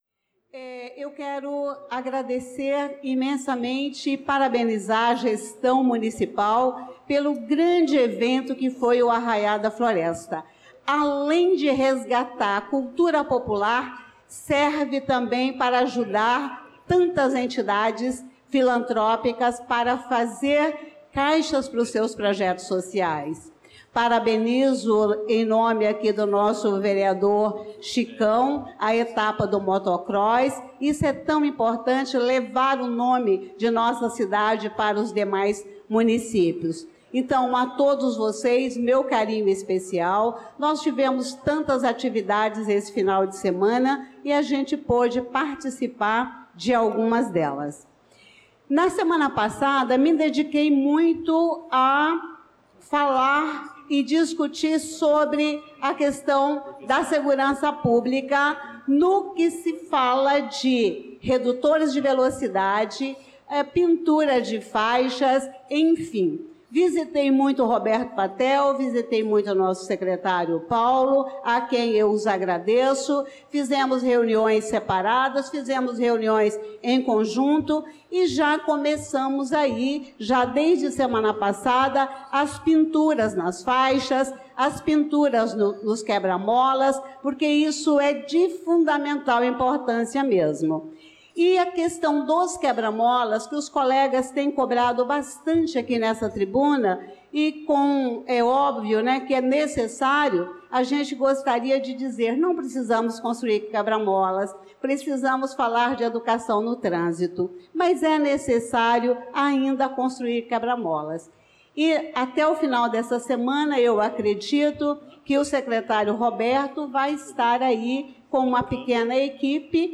Pronunciamento da vereadora Elisa Gomes na Sessão Ordinária do dia 07/07/2025.